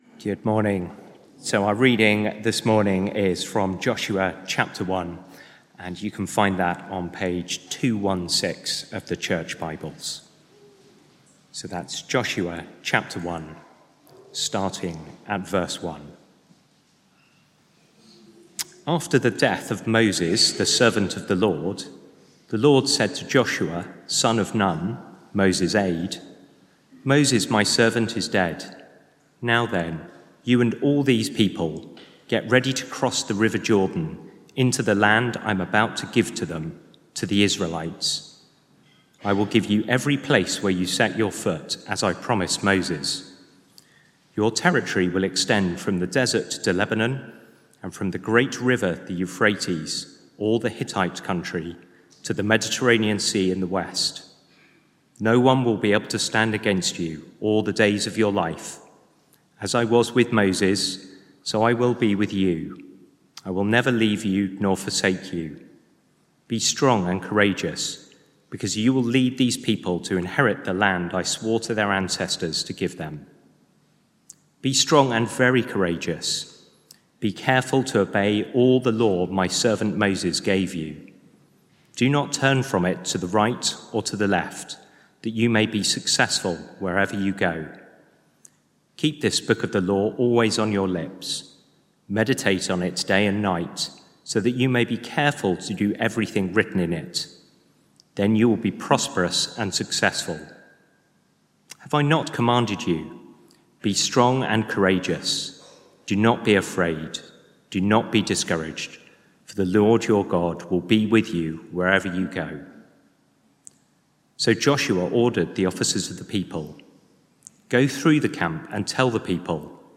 Talks at St. John's Blackheath
From Service: "10.15am Service"